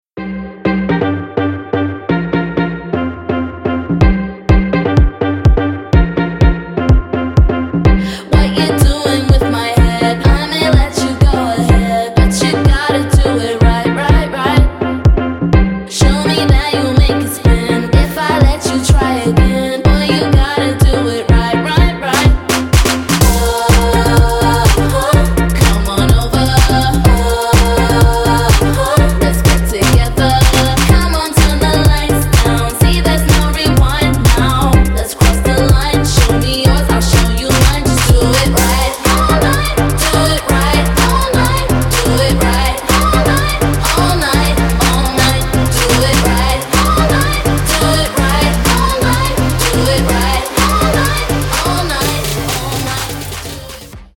женский вокал
dance
Electronic
club
vocal
Стиль: electro house